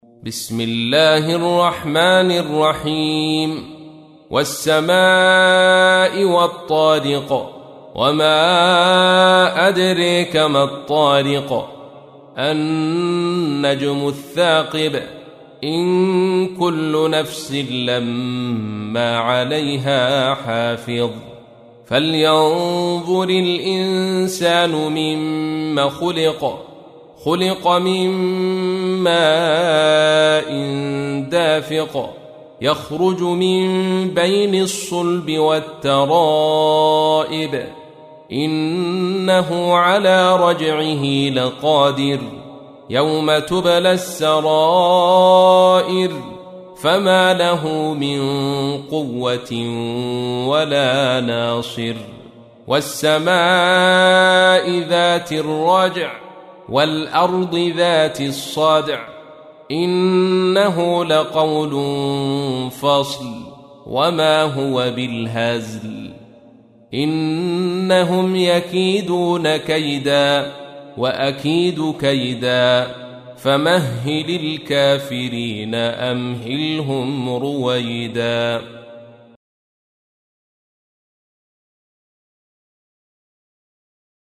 تحميل : 86. سورة الطارق / القارئ عبد الرشيد صوفي / القرآن الكريم / موقع يا حسين